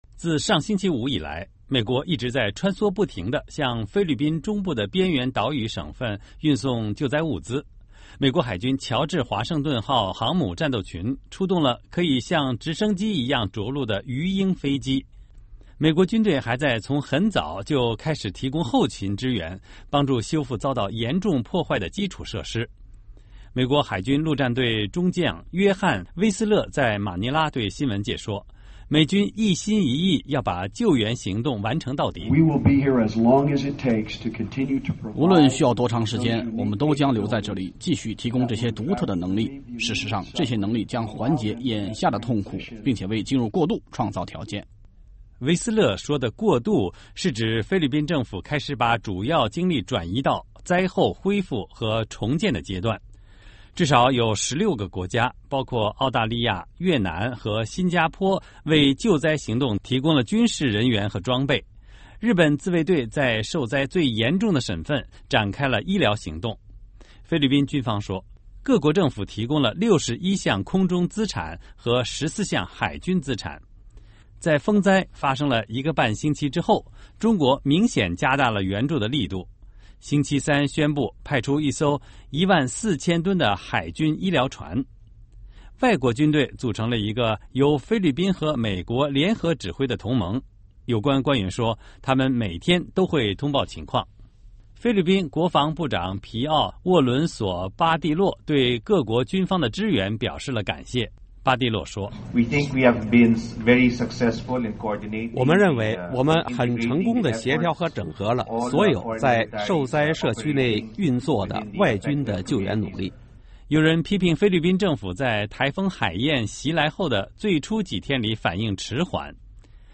音频报道:美国表示在菲律宾的救援行动将进行到底